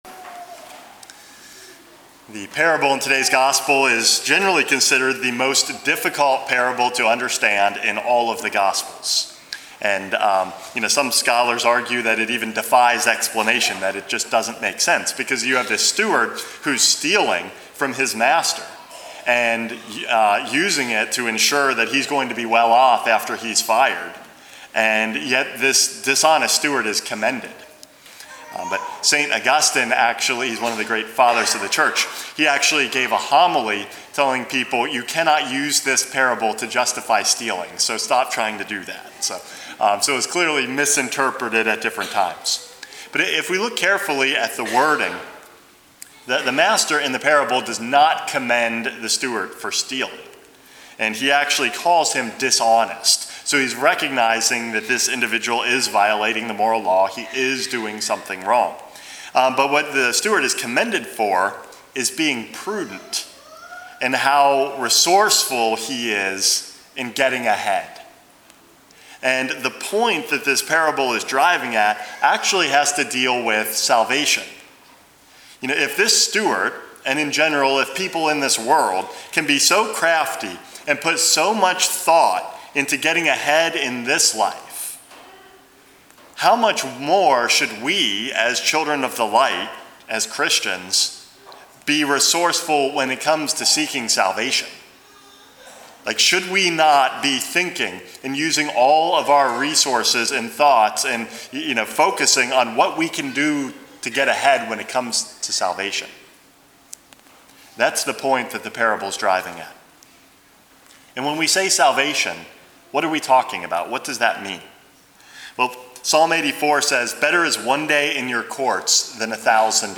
Homily #465 - Striving for Salvation